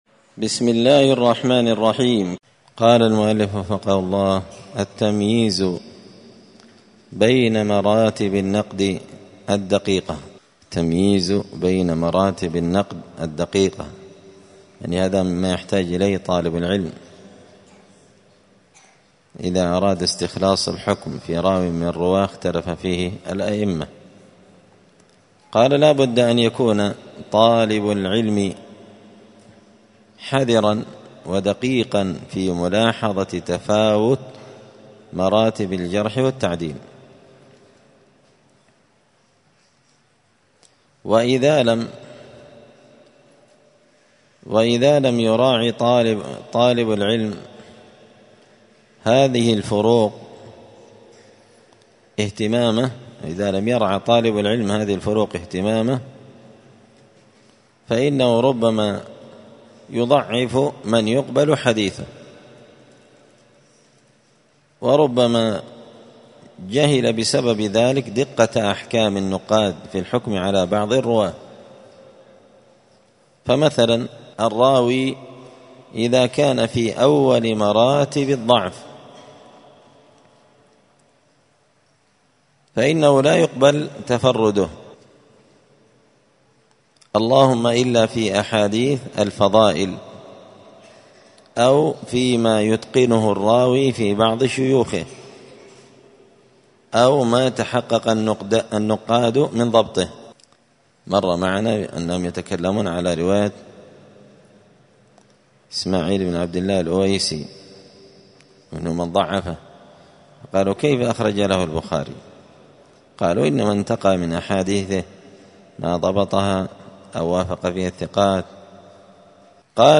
*الدرس العشرون (20) التمييز بين مراتب النقد الدقيقة*
دار الحديث السلفية بمسجد الفرقان بقشن المهرة اليمن